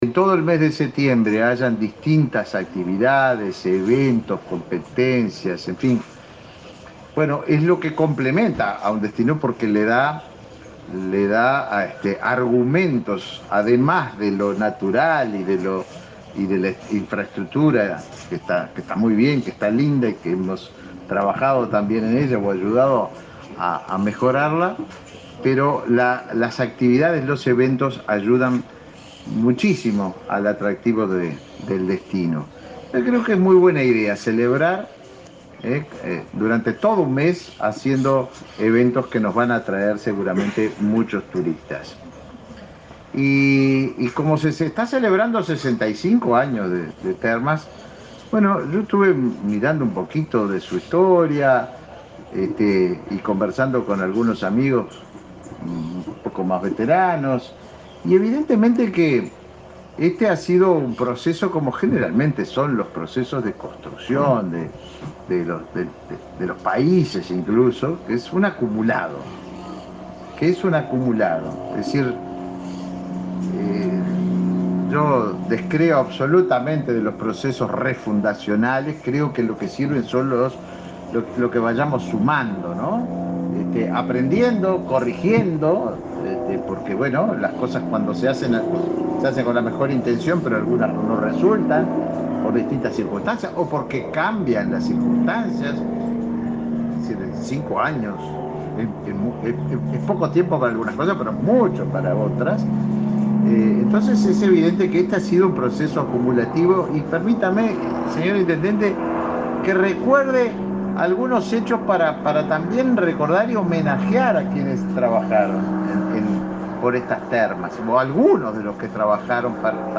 Palabras del ministro de Turismo, Tabaré Viera
El ministro de Turismo, Tabaré Viera, participó de una conferencia en Salto, donde se informó sobre inversiones para el destino Termas.